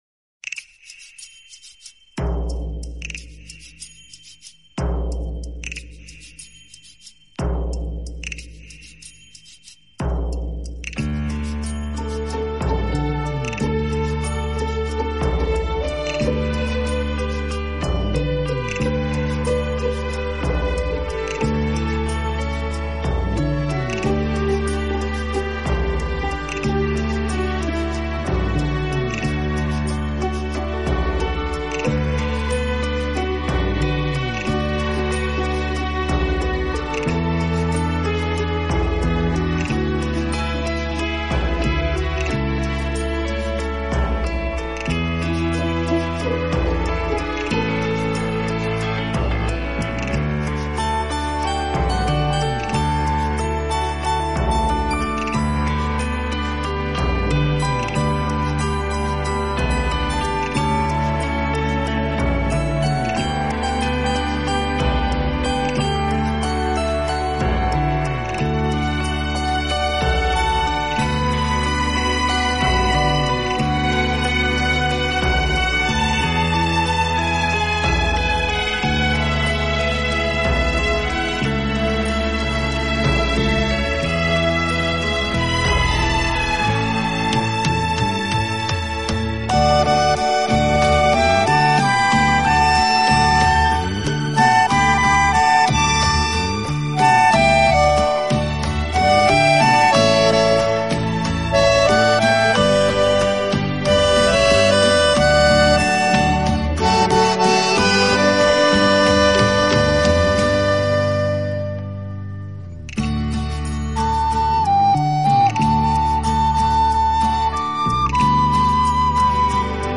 好处的管乐组合，给人以美不胜收之感。